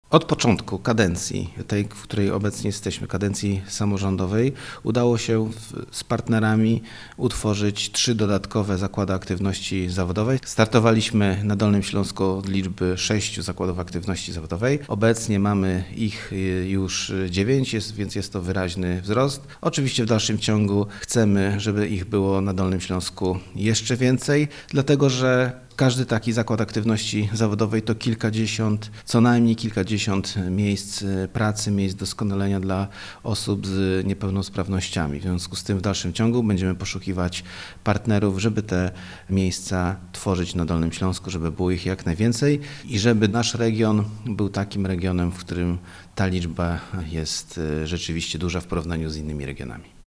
Obecnie na Dolnym Śląsku działa 9 ZAZ-ów, dodaje wicemarszałek.